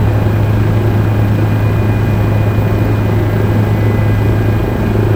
main_rotor_idle_power_inside_R.wav